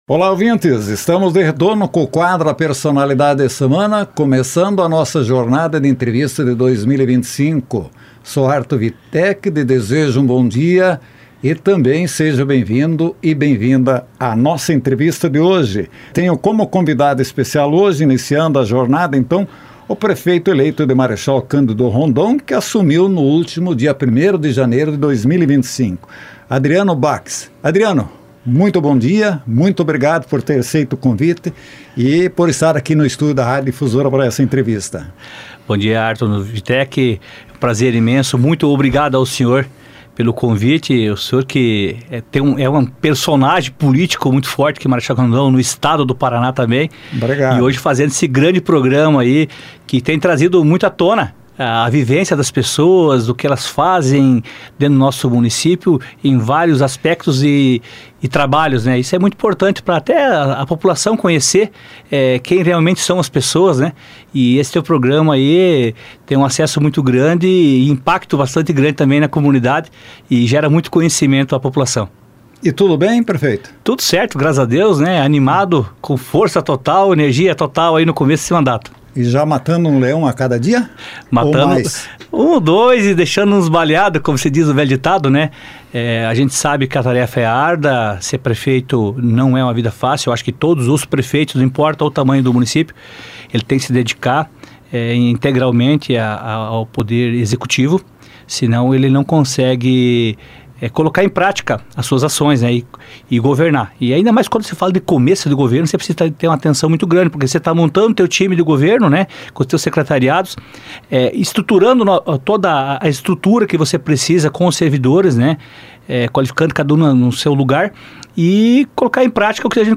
Adriano Backes, prefeito de Marechal Cândido Rondon, foi o nosso entrevistado no quadro " A Personalidade da Semana"